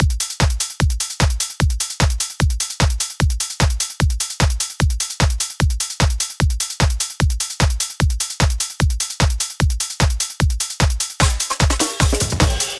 House4.wav